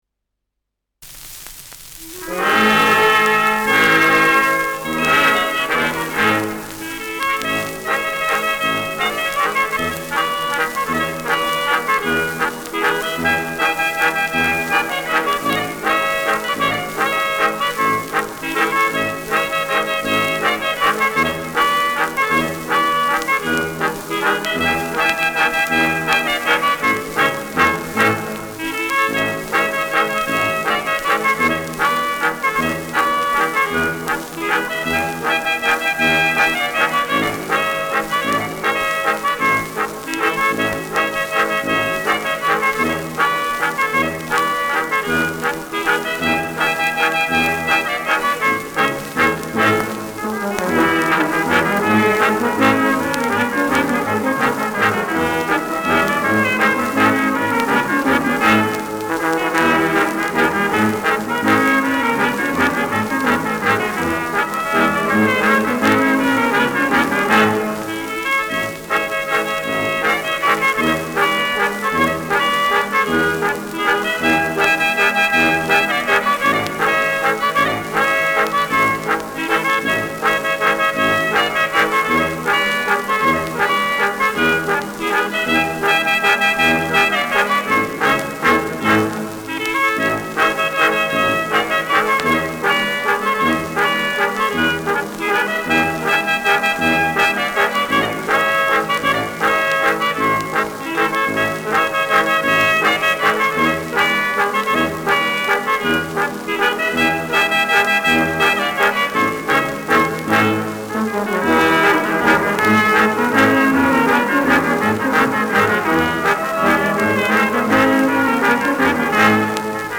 Schellackplatte
leichtes Rauschen : präsentes Knistern : abgespielt : leichtes Leiern
Kapelle Jais (Interpretation)
[München] (Aufnahmeort)